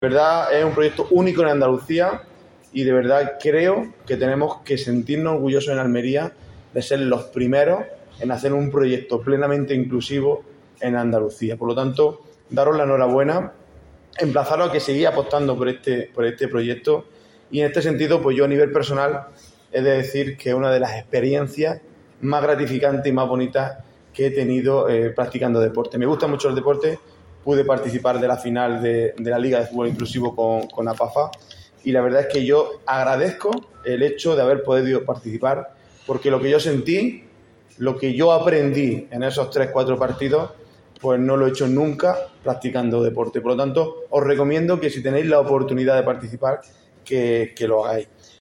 audio-diputado-de-Deportes.mp3